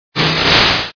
Fichier:Cri 0268 DP.ogg